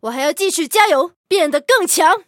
LT-35MVP语音.OGG